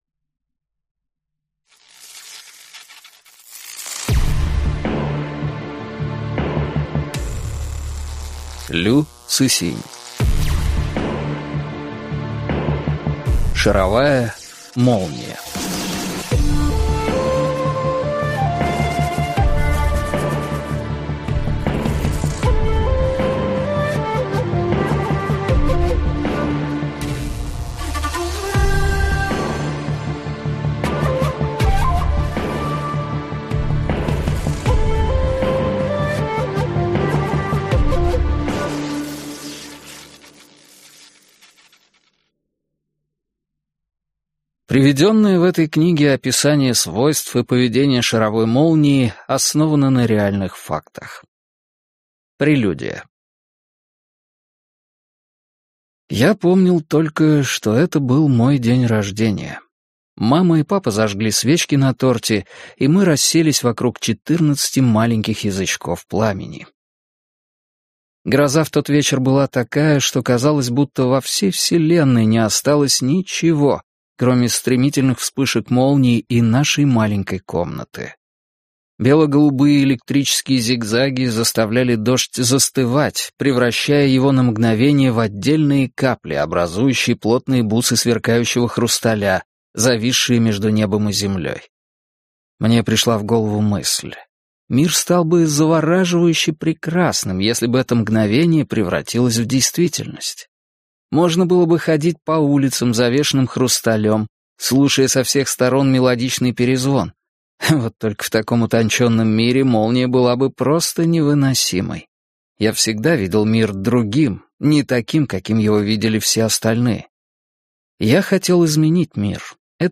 Прослушать фрагмент аудиокниги Шаровая молния Лю Цысинь Произведений: 5 Скачать бесплатно книгу Скачать в MP3 Вы скачиваете фрагмент книги, предоставленный издательством